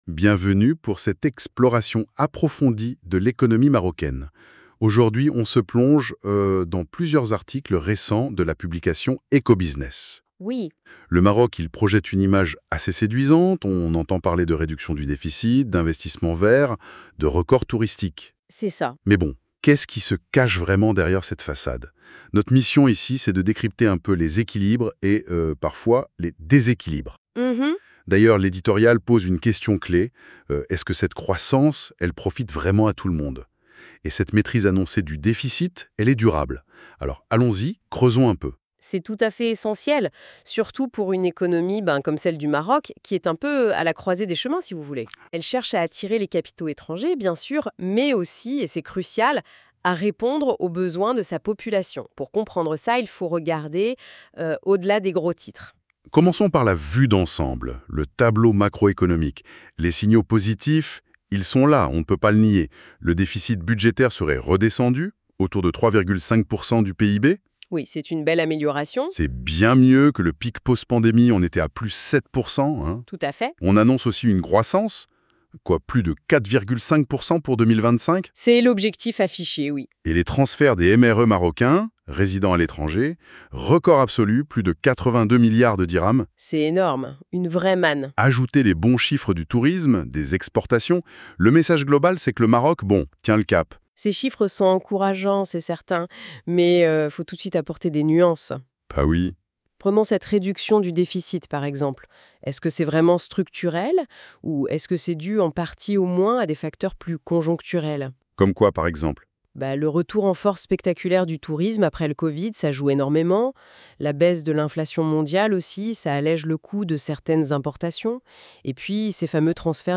Debat-Podcats Eco Business 15 Sept.mp3 (17.21 Mo)